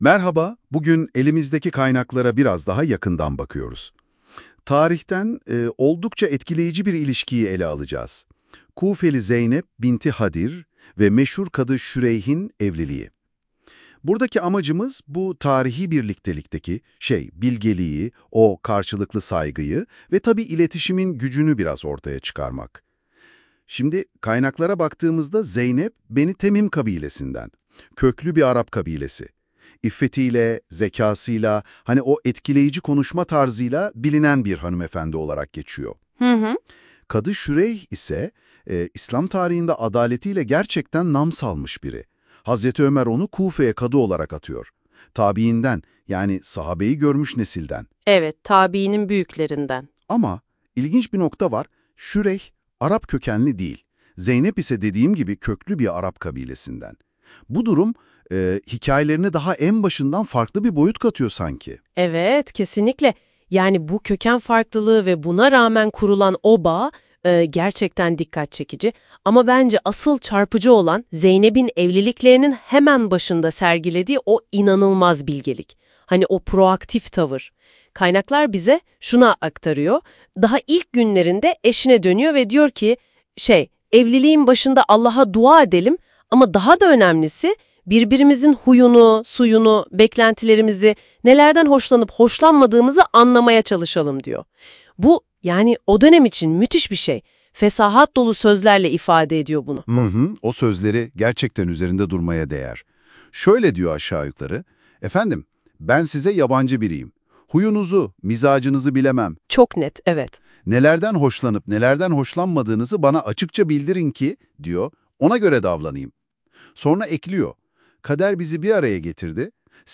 Yapay zekanın konuyu nasıl özetlediğini dinlemek isterseniz oynatıcıdan dinleyebilirsniz.